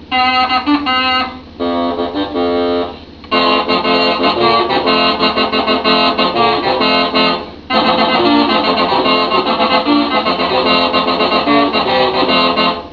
Wave files are sounds that you record with a microphone. The clicking noises in the background of some songs is my metronome.
Here is a krum trio.
krumtrio.wav